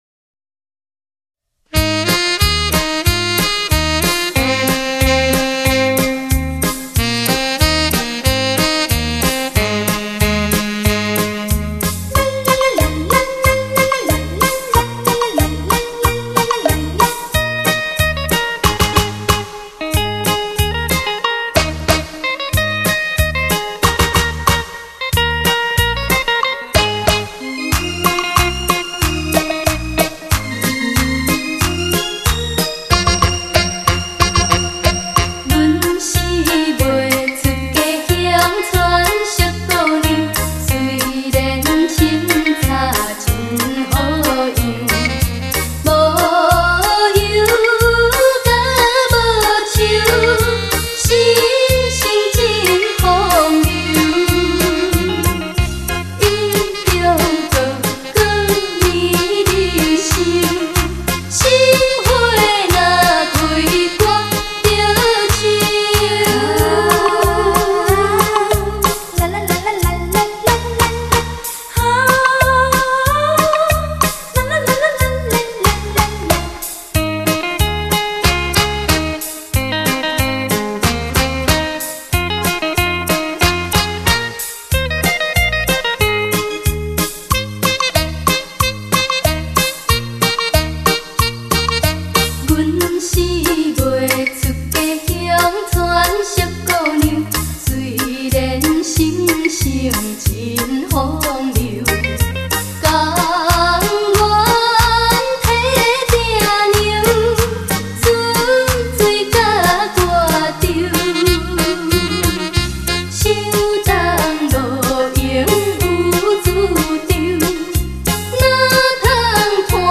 重新编曲 全新演唱
女声实力歌手 保证让您百听不厌
12首台湾经典情歌一次爱个够